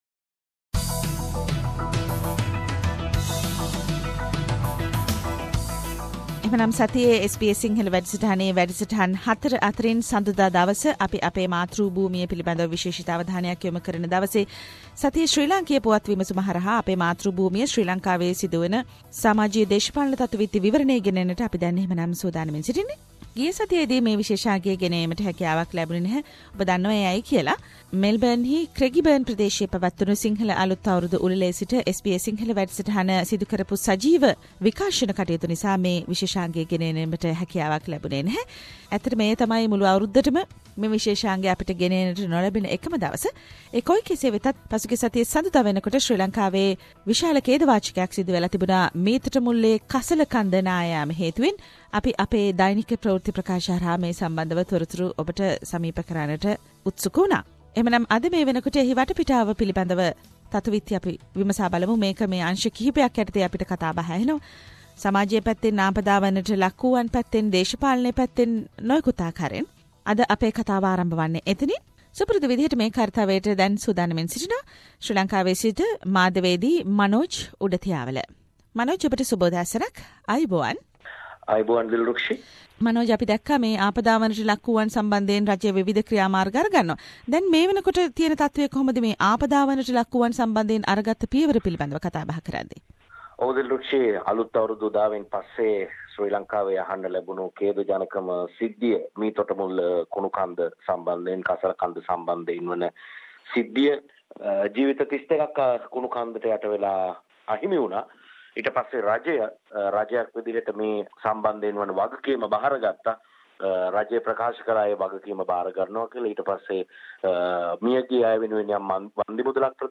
Weekly Sri Lankan political highlights - The comprehensive wrap up of the highlighted political incidents in Sri Lanka…..Senior Journalist - News and current affair